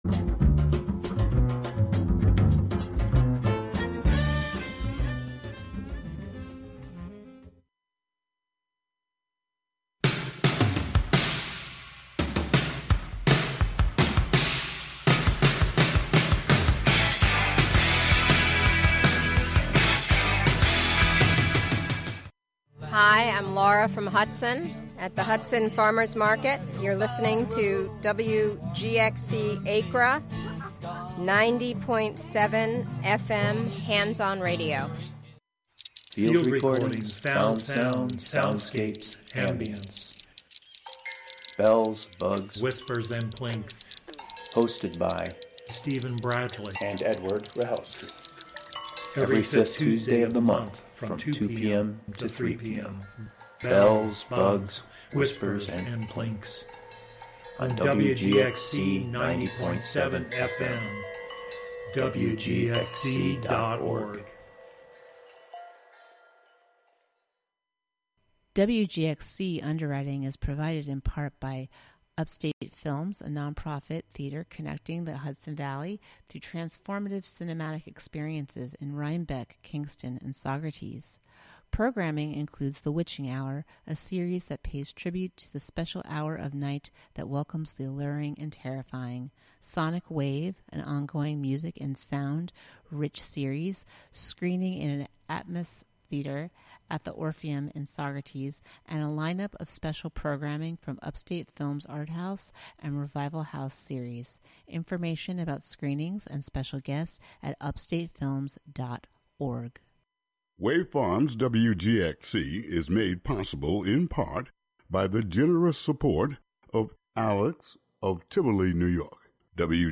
8pm Although Brazilian singer-songwriter and musician...